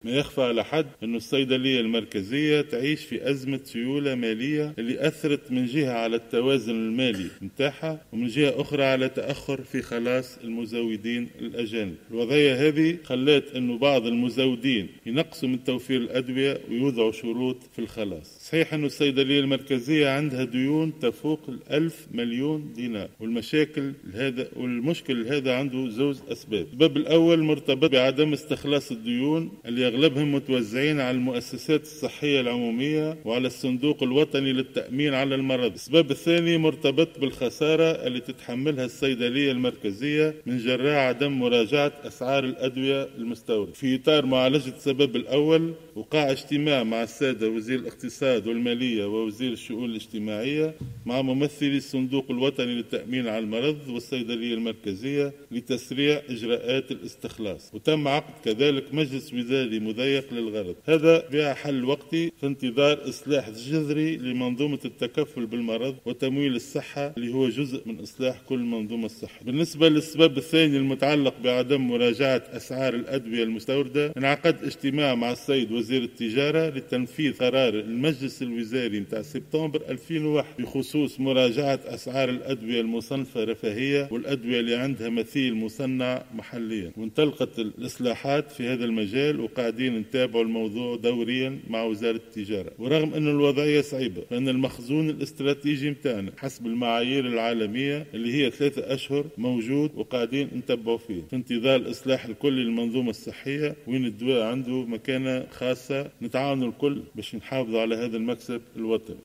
وأضاف الوزير على هامش جلسة عامة بالبرلمان ، أن ديون الصيدلية المركزية بلغت ألف مليون دينار ، أغلبها ديون لفائدة الصندوق الوطني للتأمين على المرض وتراكم الديون لدى المزودين ، مما أثّر على التزود بالمواد الأوّلية للصناعة المحلية و كذلك على التزوّد بالادوية من الخارج .